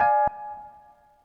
Banks Keyz.wav